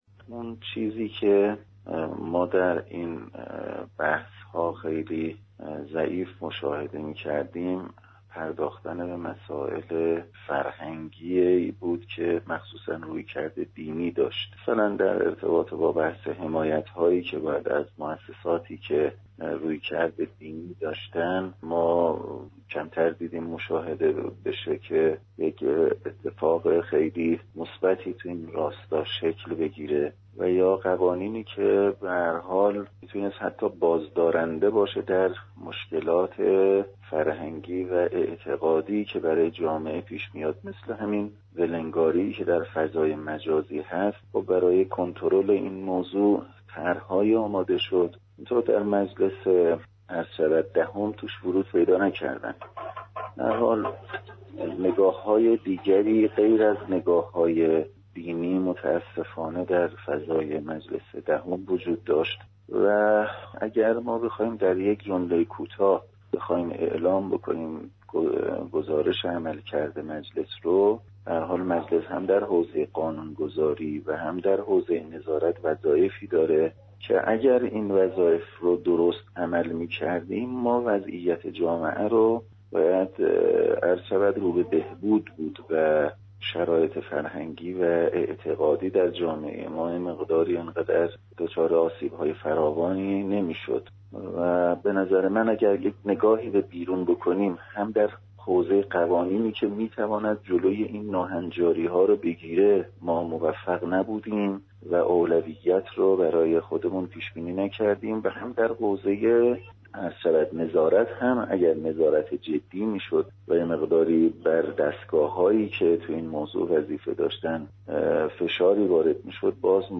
حجت الاسلام پژمانفر در گفتگو با خبر رادیومعارف بی توجهی به ساماندهی فضای مجازی را یكی از كوتاهی های مجلس دراین زمینه برشمرد.